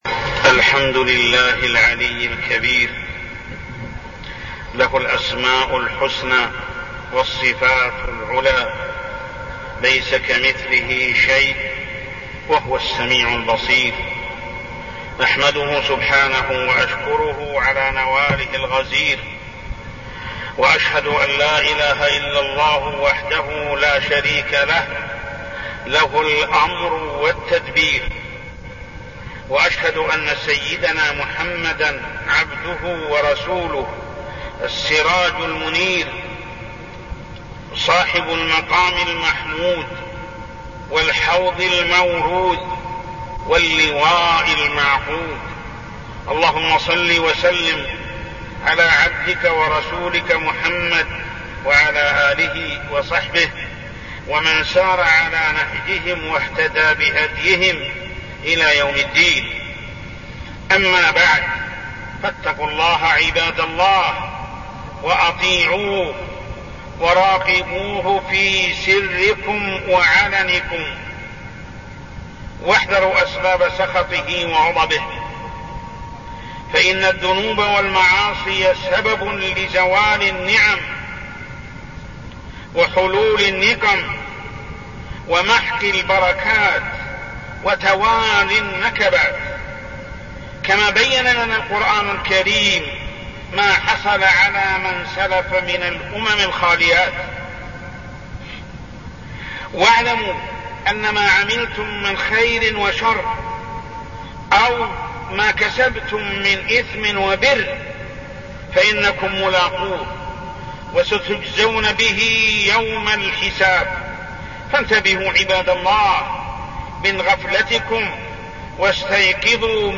تاريخ النشر ١٠ محرم ١٤١٣ هـ المكان: المسجد الحرام الشيخ: محمد بن عبد الله السبيل محمد بن عبد الله السبيل إغراق فرعون ونجاة موسى عليه السلام